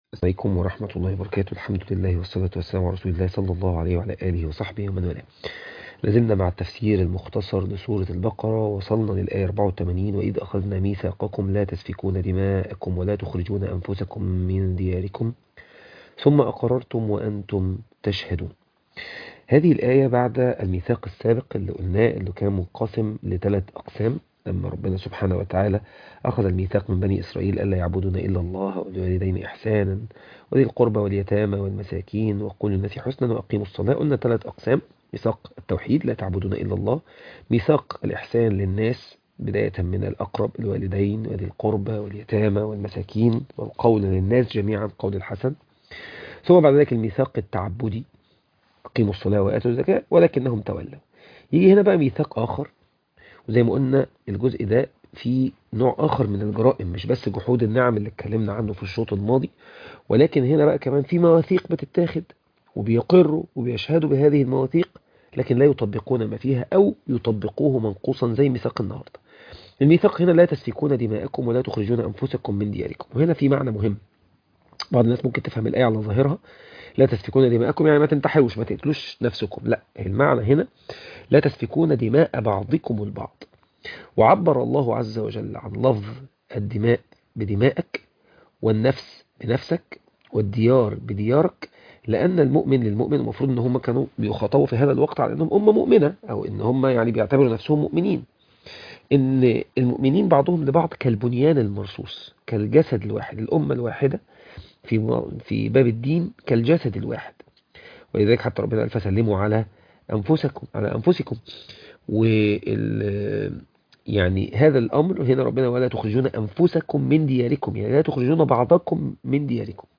سورة البقرة 22 الآيات 84 و 85 التلاوة